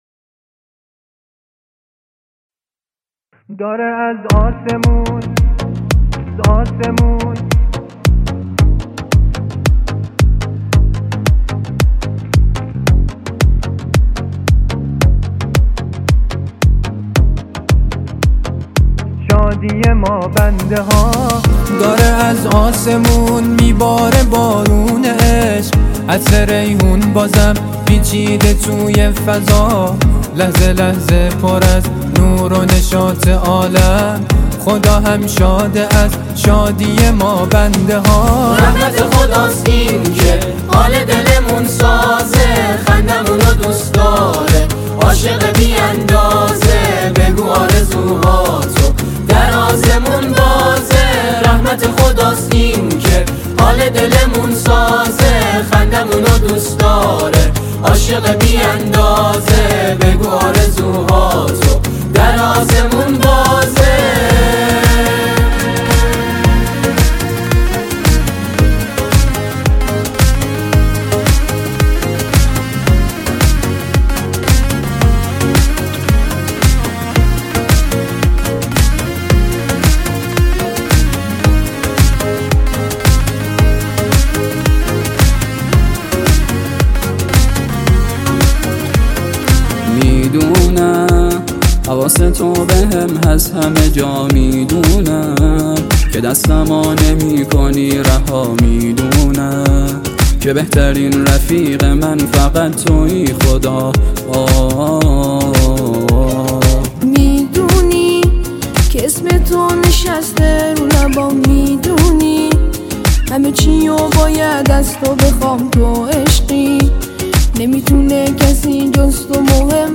نماهنگ شاد و دل‌انگیز
ژانر: سرود